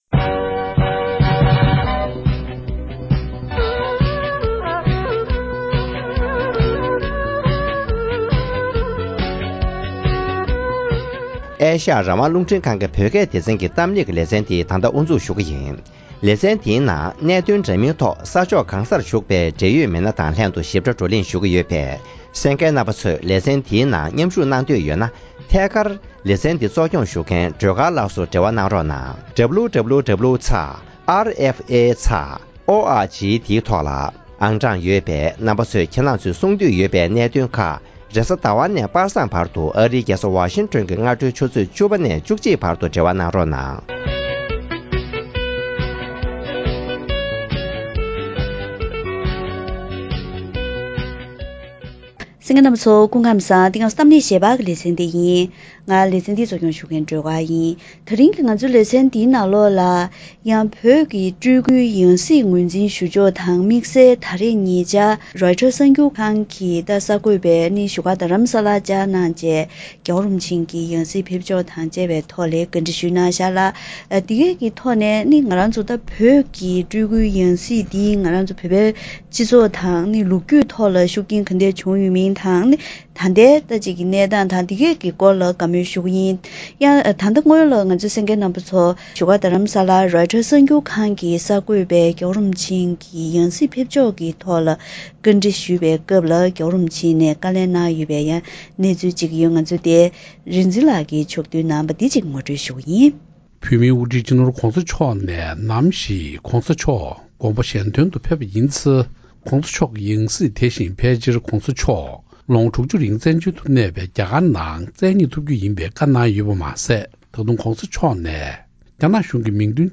༧རྒྱལ་བའི་ཡང་སྲིད་ཕེབས་ཕྱོགས་དང་འབྲེལ་བོད་ཀྱི་སྤྲུལ་སྐུའི་ཡང་སྲིད་ངོས་འཛིན་ཞུ་སྲོལ་གྱི་ལོ་རྒྱུས་གནས་སྟངས་དང་ད་ལྟའི་དངོས་ཡོད་གནས་ཚུལ་ཁག་གི་ཐོག་བགྲོ་གླེང་ཞུས་པ།